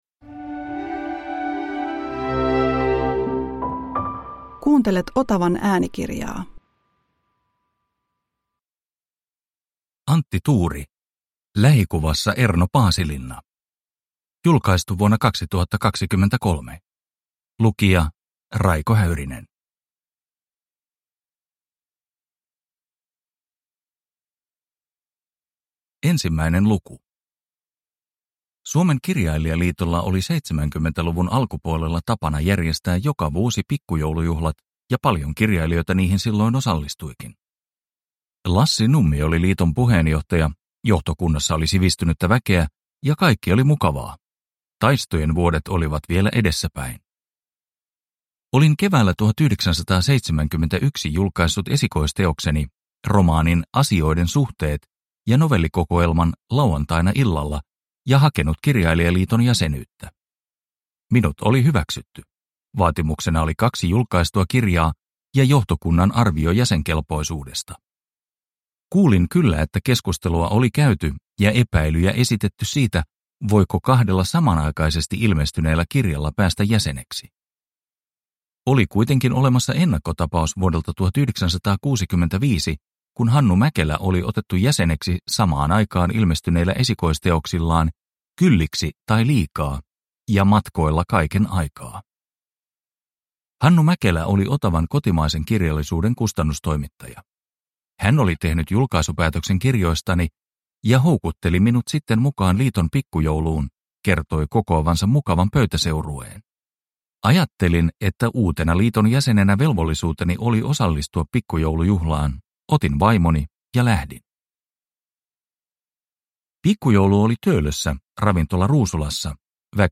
Lähikuvassa Erno Paasilinna (ljudbok) av Antti Tuuri